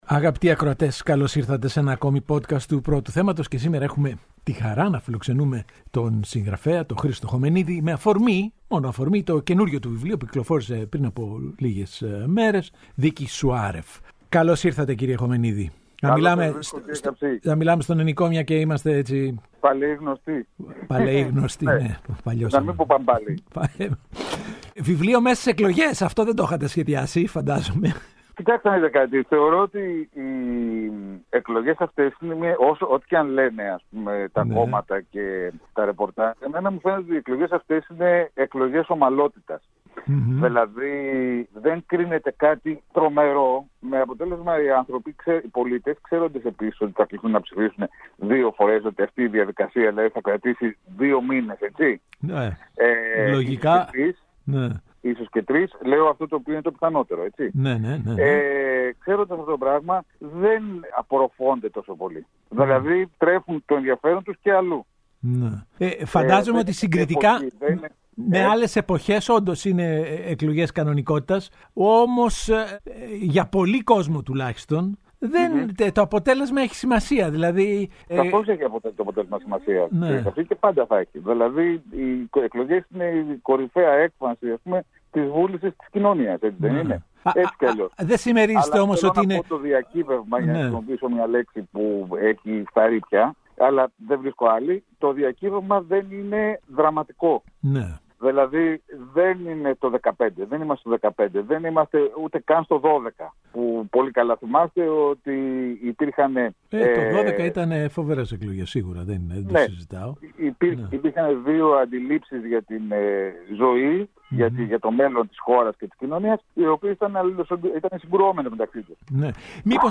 Ο Παντελής Καψής συζητά με τον Χρήστο Χωμενίδη: Γιατί θα νικήσει ο Μητσοτάκης στις εκλογές